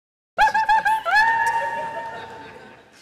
cuica-vai-que-cola.mp3